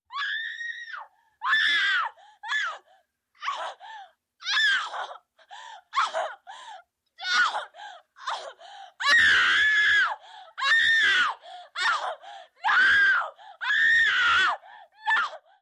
Звуки крика женщины
Вопль девушки, за которой гонится убийца